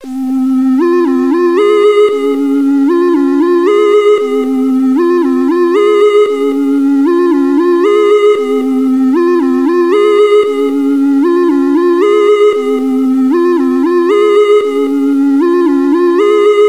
标签： 115 bpm Trip Hop Loops Pad Loops 2.81 MB wav Key : C
声道立体声